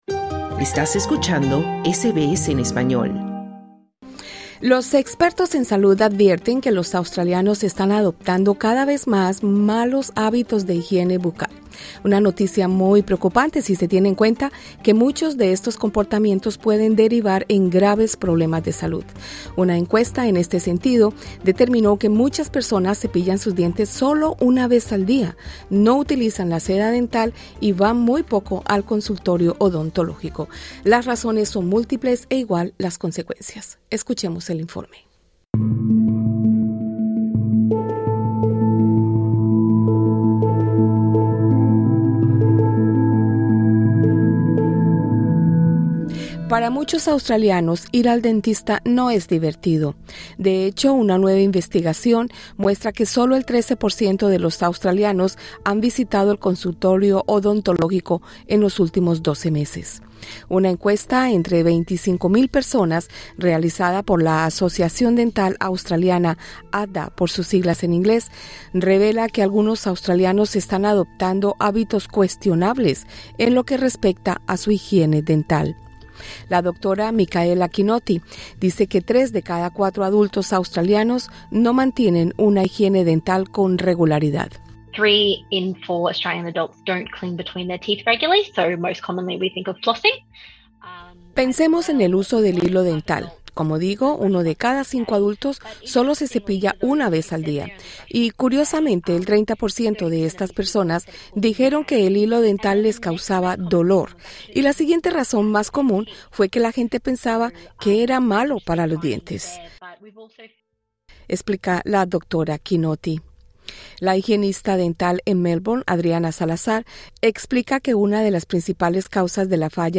Entrevistada por SBS Spanish